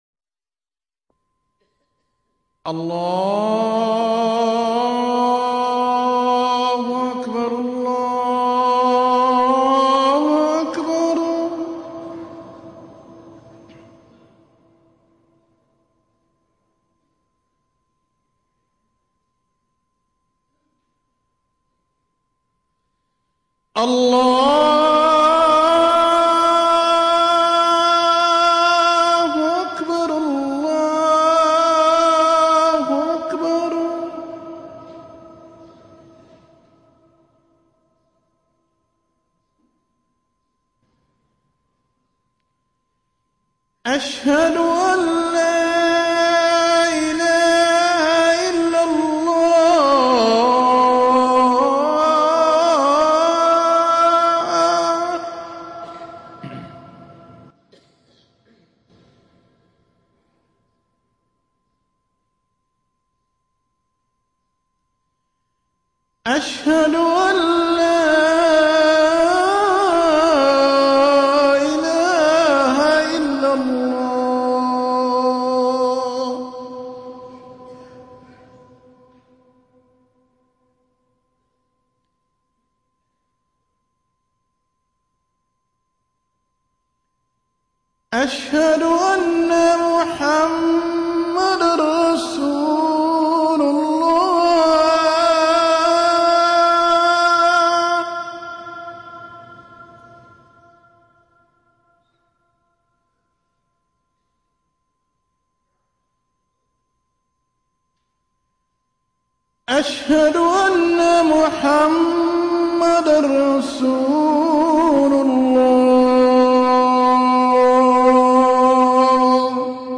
أذان
المكان: المسجد النبوي الشيخ